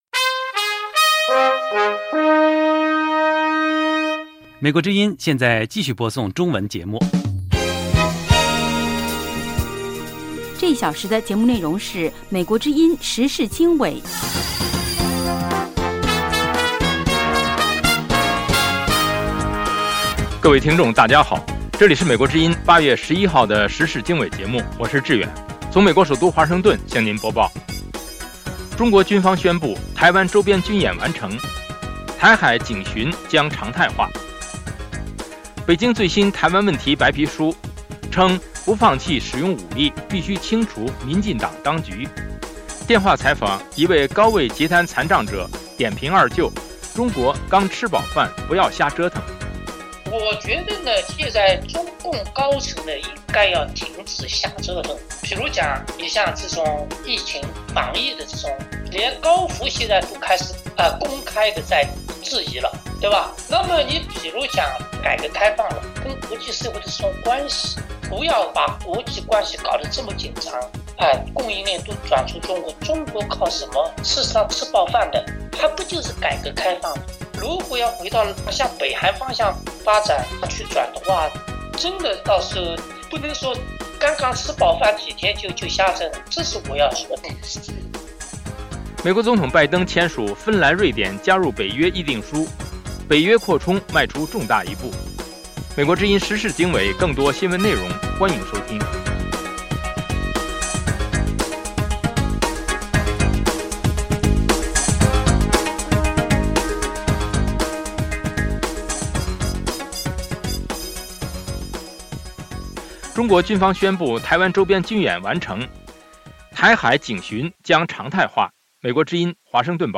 时事经纬(2022年8月11日)：1/北京最新台湾问题白皮书：不放弃使用武力,必须清除民进党当局。2/电话采访:一位高位截瘫残障者点评《二舅》中国刚吃饱饭不要瞎折腾。